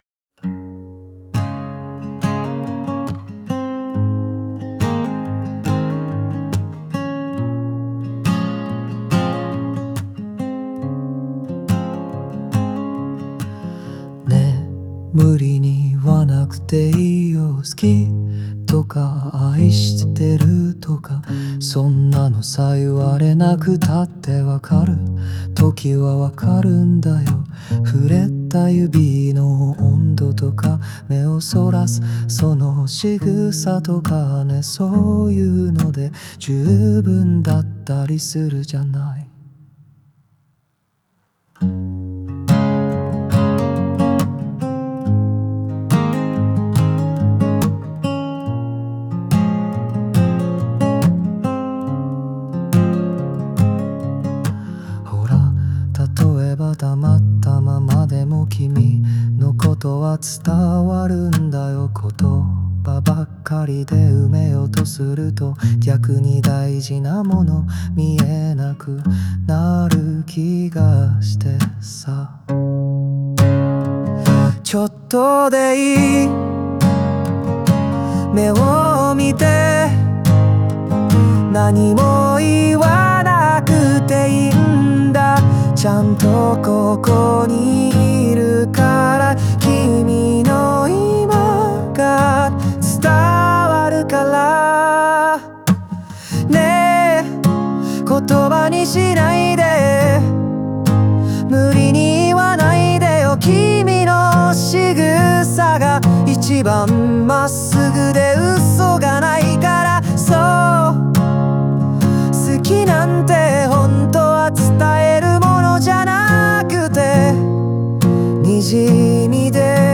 オリジナル曲♪
この歌詞は、言葉に頼らずとも愛は伝わるという想いを、語りかけるような口調で描いた作品です。